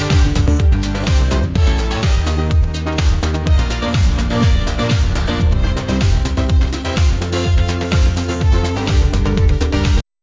Controllable Text-to-Music Generation